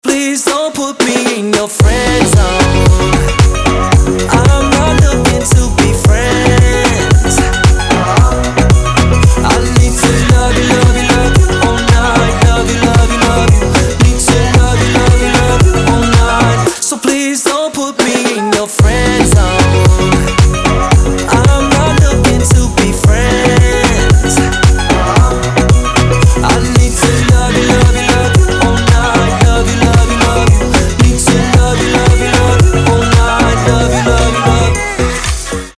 • Качество: 320, Stereo
поп
зажигательные
dance
RnB